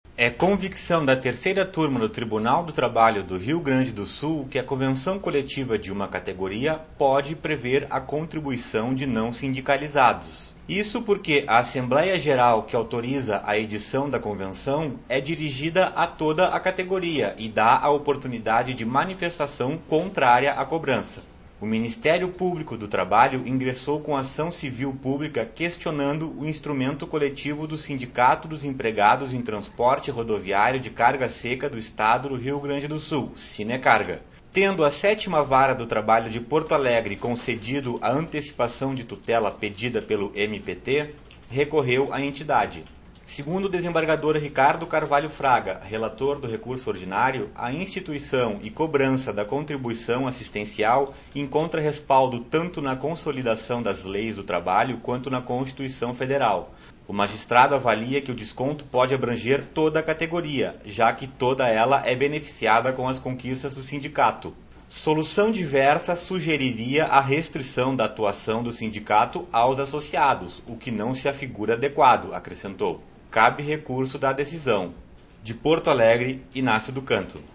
Áudio da notícia - 01